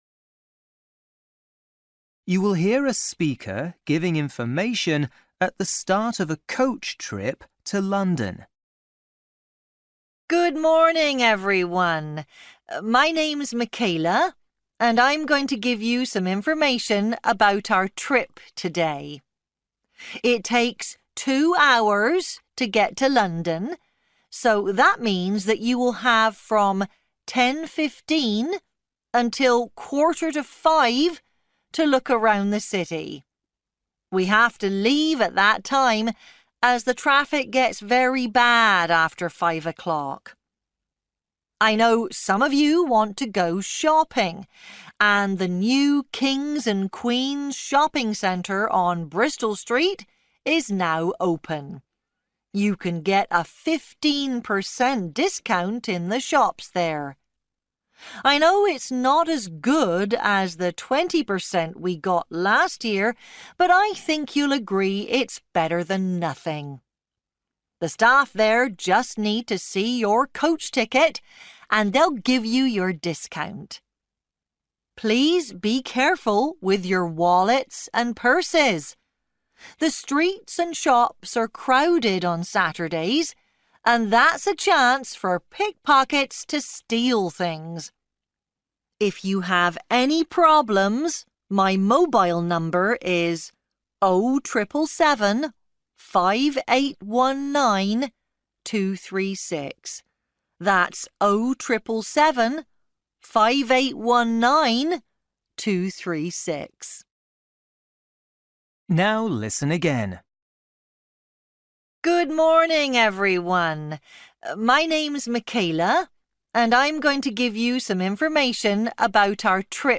You will hear a speaker giving information at the start of a coach trip to London.